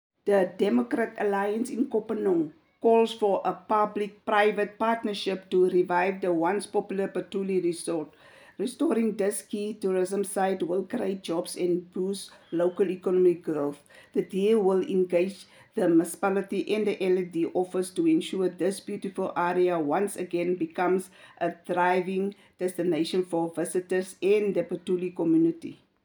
Issued by Cllr. Hessie Shebe – DA Councillor Kopanong Local Municipality
Afrikaans soundbites by Cllr Hessie Shebe and